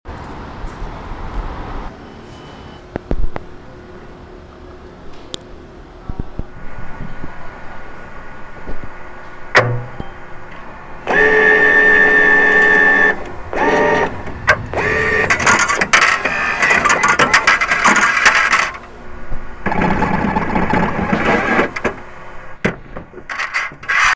🎧 Using the MixPre-6, I captured some amazing sounds with a contact microphone and an electromagnetic pick-up coil. I even recorded the ticket terminal at a SkyTrain station, which added some fascinating mechanical and electronic sounds to the mix. The combination of these mics provided unique textures and depth to the recordings.